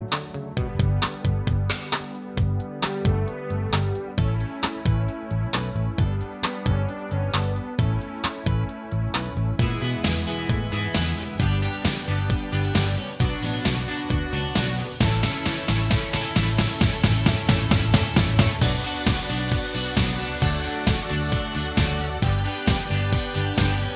Minus Main Guitar Pop (1980s) 4:12 Buy £1.50